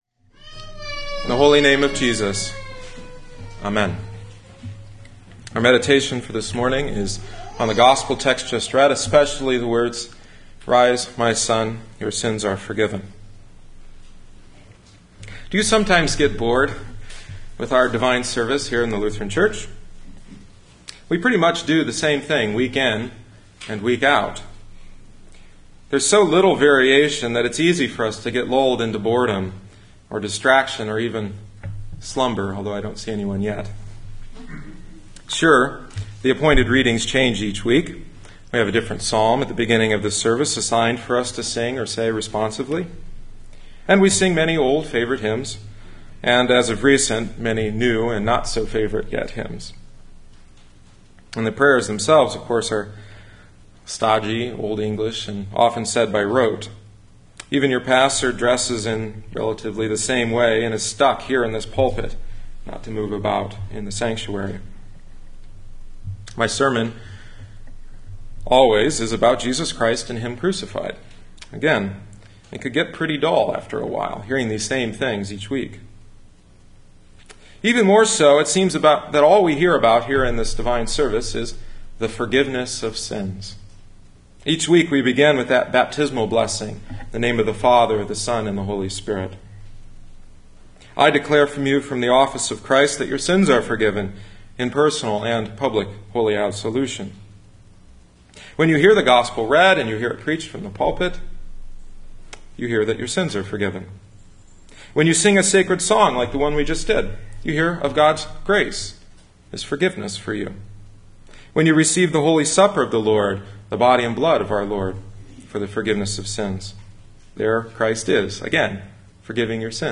October 2010 Nineteenth Sunday after Trinity Matthew 9:1-8; Ephesians 4:22-28